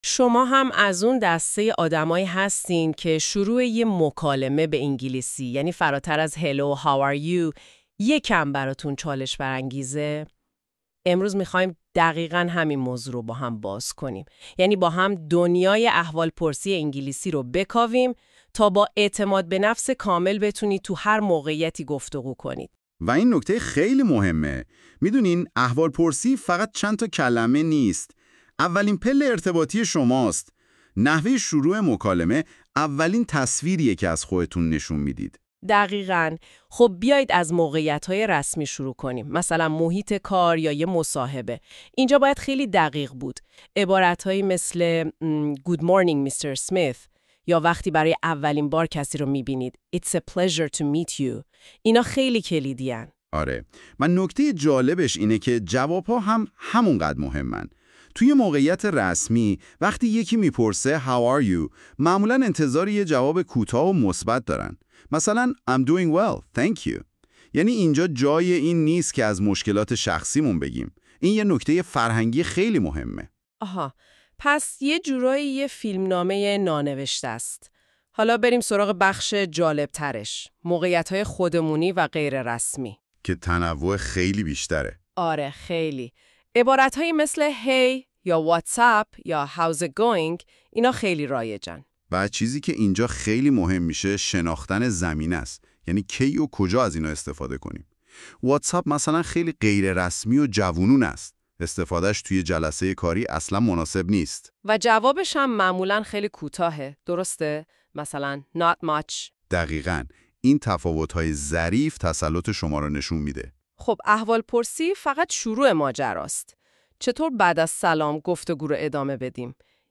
english-greeting-conversation.mp3